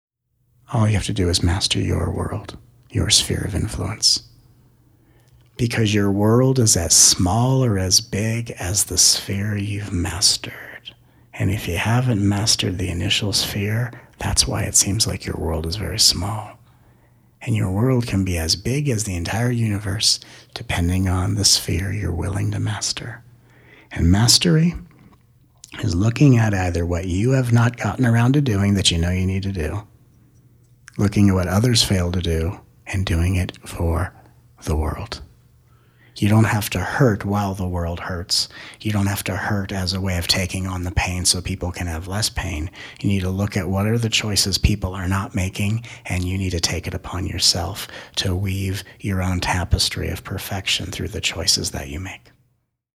Join us for this incredible download recorded during the 2017 Denver and Boulder Colorado Tour. 13 tracks; total time 7 hrs, 58 mins.